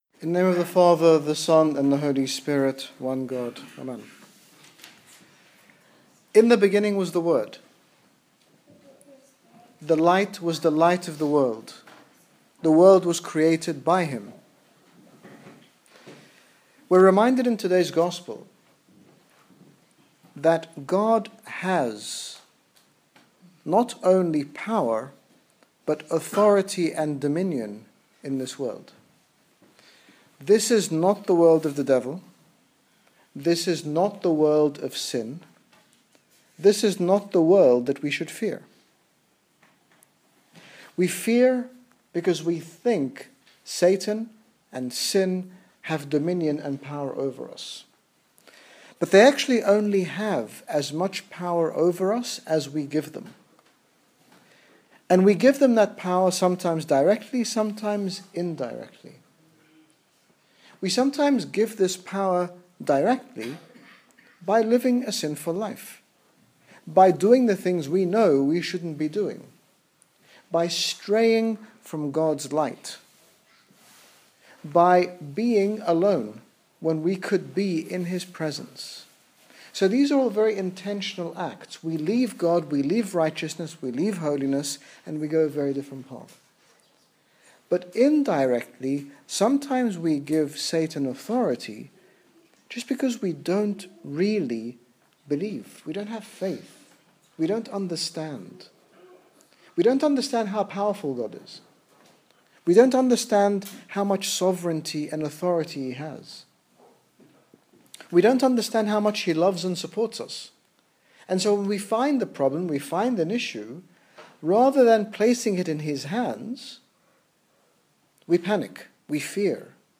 In this short sermon, His Grace Bishop Angaelos, General Bishop of the Coptic Orthodox Church in the United Kingdom, speaks to us about being confident that God, Who lives within us, desires to be with us in every challenge no matter how seemingly great or small it is.